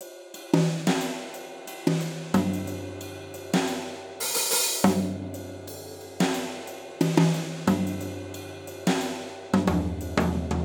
Unison Jazz - 10 - 90bpm - Tops.wav